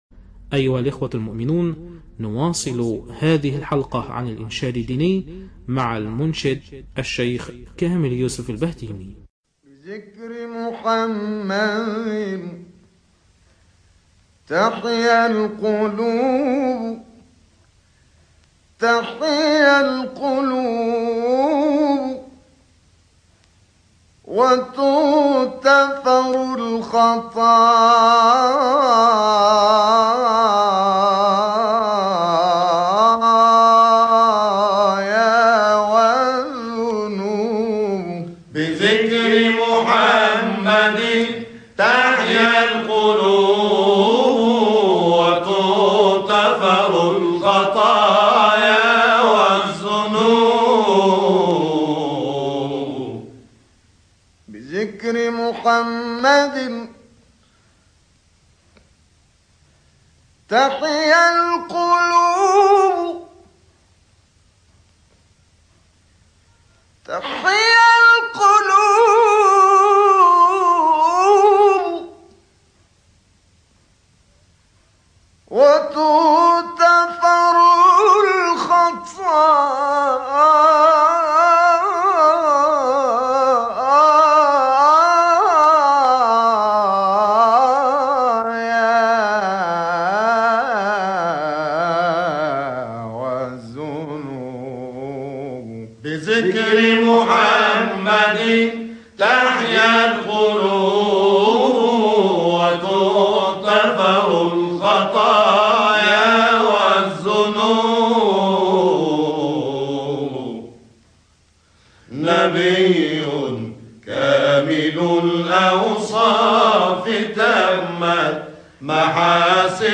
گروه شبکه اجتماعی: ابتهالی کوتاه با صوت کامل یوسف البهتیمی در مدح و ثنای پیامبر اکرم(ص) همزمان با آغاز هفته وحدت ارائه می‌شود.
به گزارش خبرگزاری بین‌المللی قرآن(ایکنا) «بذکر محمد تحیا القلوب» نام ابتهال کوتاهی از کامل یوسف البهتیمی، قاری و مبتهل برجسته مصری است که به مناسبت آغاز هفته وحدت، در مدح و ثنای پیامبر اکرم(ص) در کانال تلگرامی اکبرالقراء منتشر شده است.
برچسب ها: خبرگزاری قرآن ، ایکنا ، شبکه اجتماعی ، ابتهال ، کامل یوسف البهتیمی ، مدح پیامبر ، هفته وحدت ، قرآن ، iqna